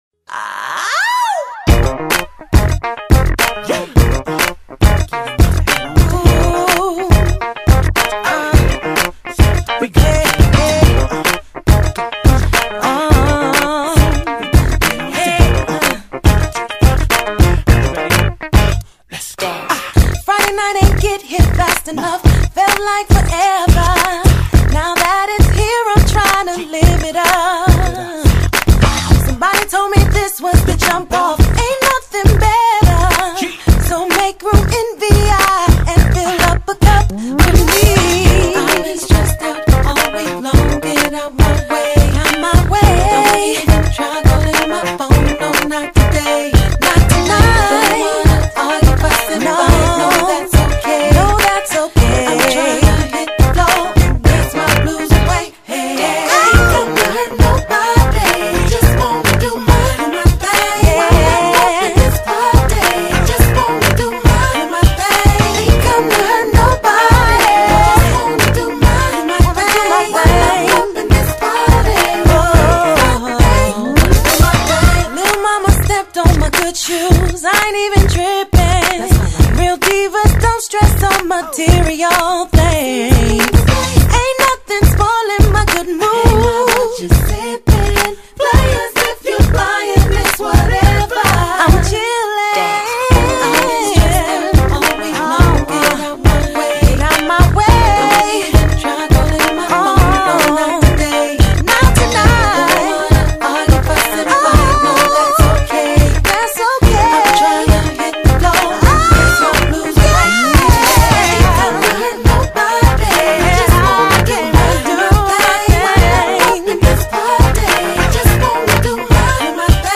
专辑类型：Hip-Hop,Urban